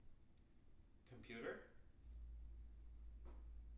tng-computer-335.wav